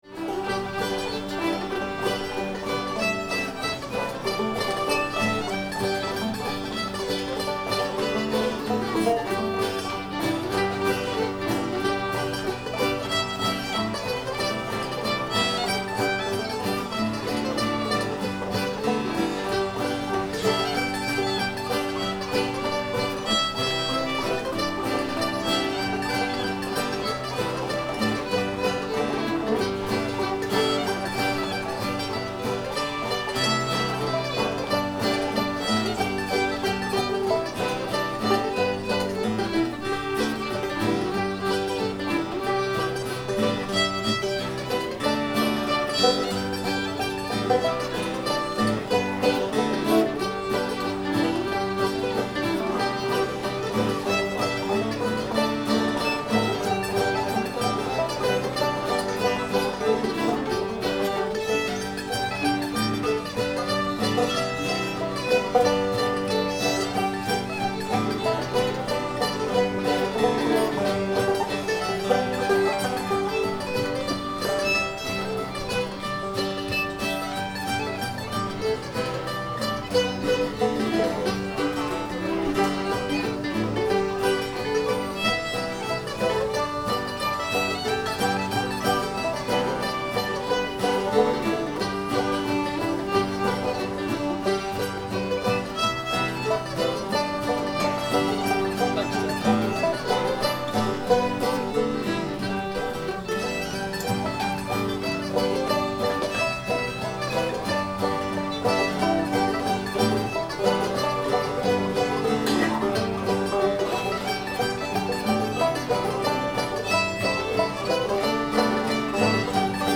big footed man in the sandy lot [G]